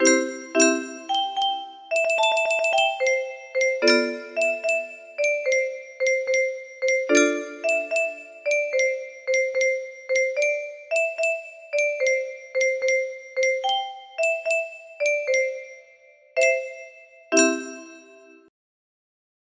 M2-M6 music box melody